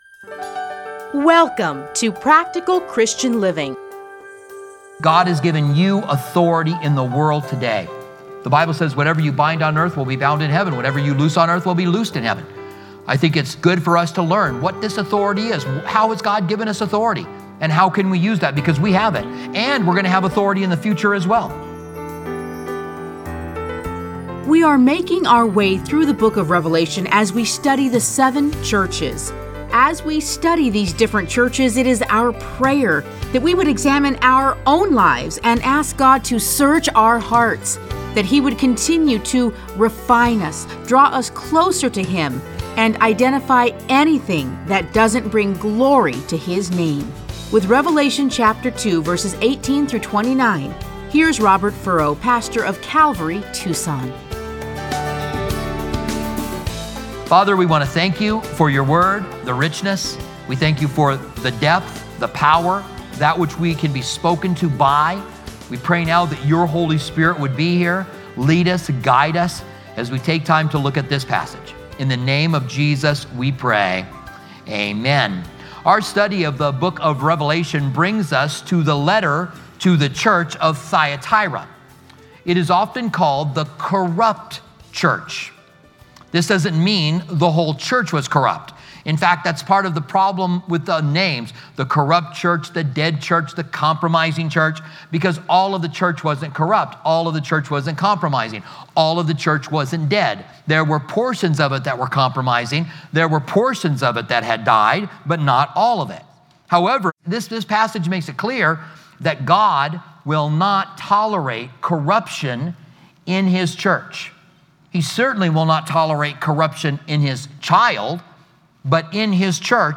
Listen to a teaching from Revelation 2:18-29.